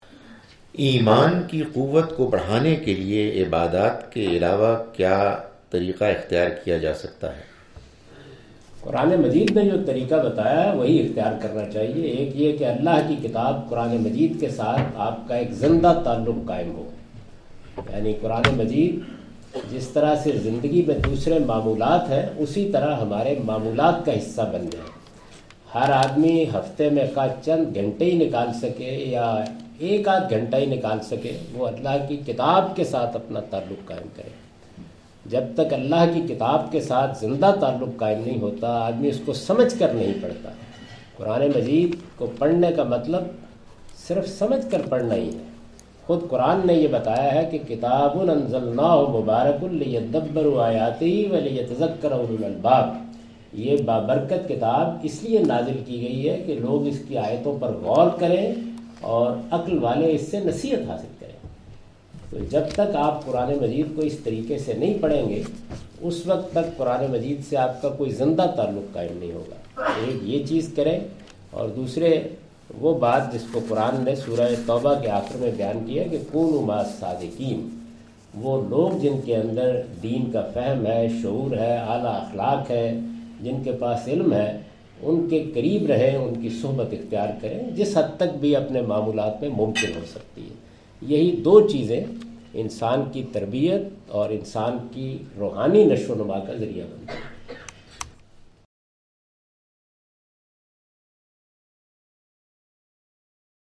Javed Ahmad Ghamidi answers the question,"How to Strengthen Faith? " during his UK visit in Manchester on March 06, 2016
جاوید احمد غامدی اپنے دورہ برطانیہ کے دوران مانچسٹر میں " ایمان کو کیسے مضبوط کیا جائے؟" سے متعلق ایک سوال کا جواب دے رہے ہیں۔